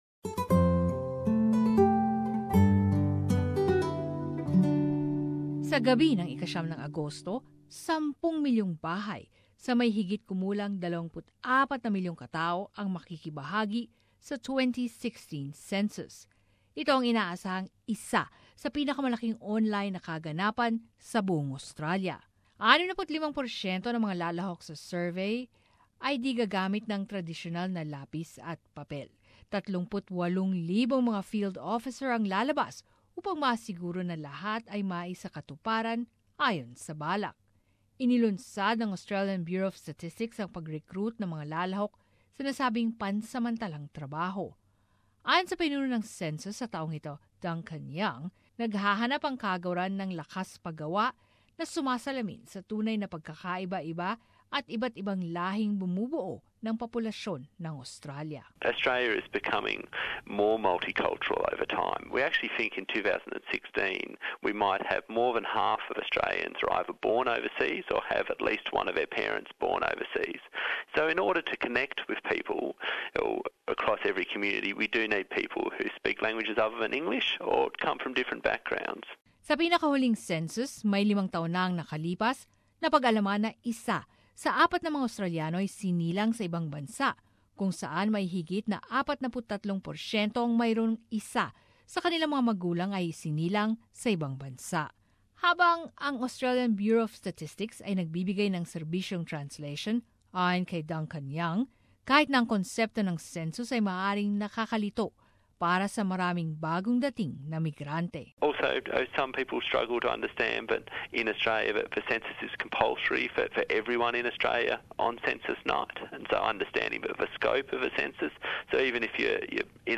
Balitang Bisayas. Summary of latest news in the region